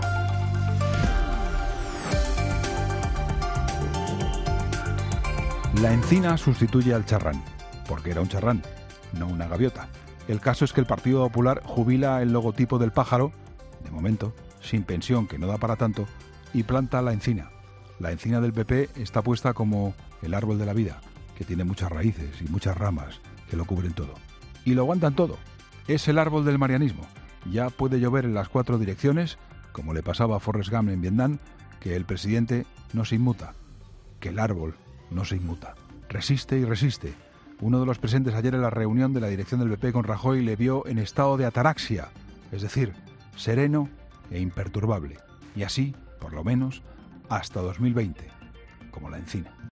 AUDIO: Escucha el análisis de actualidad del director de 'La Linterna', Juan Pablo Colmenarejo, en 'Herrera en COPE'